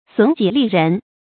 損己利人 注音： ㄙㄨㄣˇ ㄐㄧˇ ㄌㄧˋ ㄖㄣˊ 讀音讀法： 意思解釋： 損害自己，使別人得利。